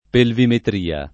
pelvimetria
[ pelvimetr & a ]